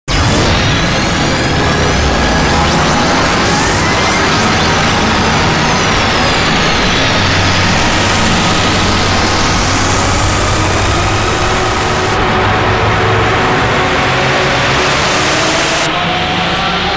دانلود آهنگ رادیو 9 از افکت صوتی اشیاء
جلوه های صوتی